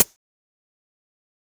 • Shiny Hihat Sample D Key 14.wav
Royality free hat tuned to the D note. Loudest frequency: 9533Hz
shiny-hihat-sample-d-key-14-0JY.wav